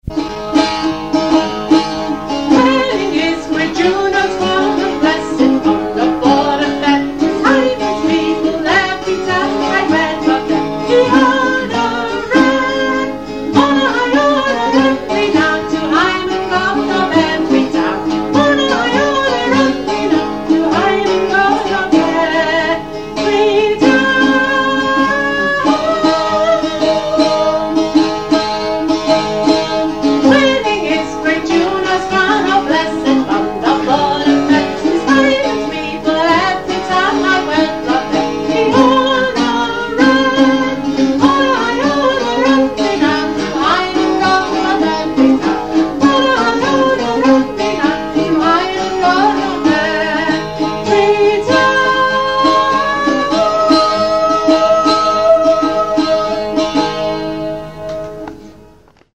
Hillbark Players - Bringing open-air Shakespeare to the Wirral